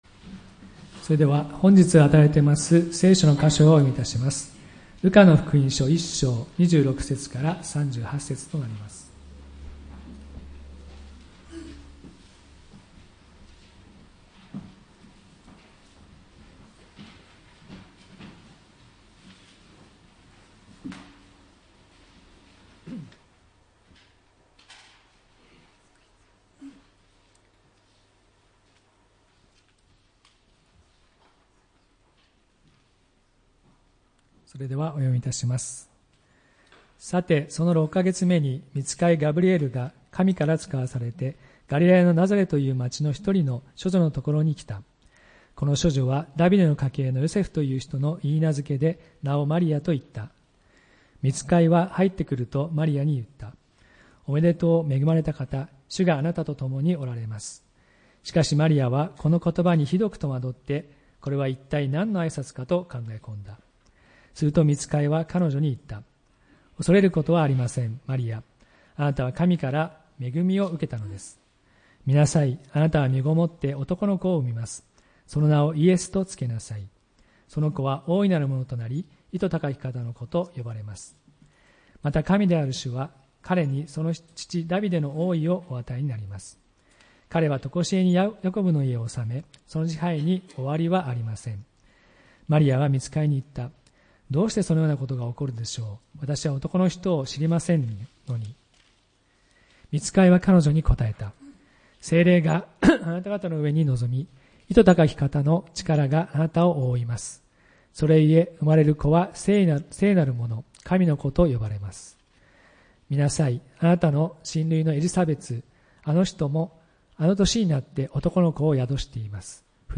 礼拝メッセージ「その名はイエス」(12月22日）